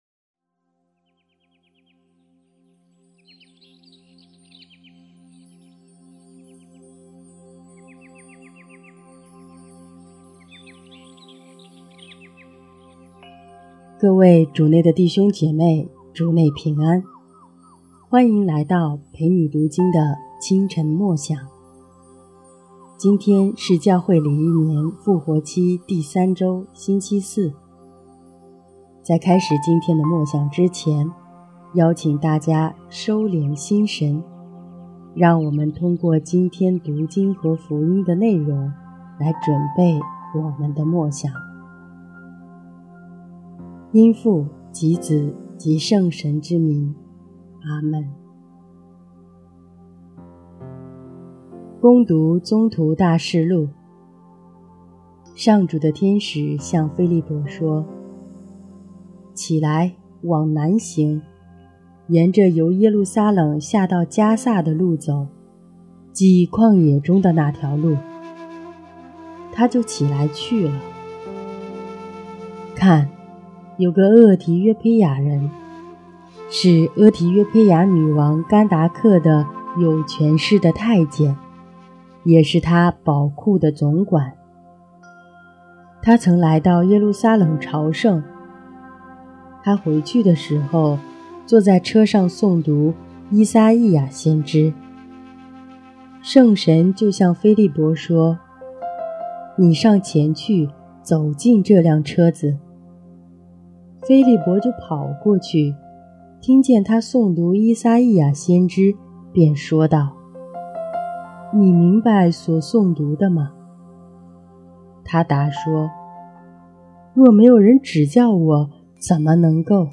这次声音有点小了！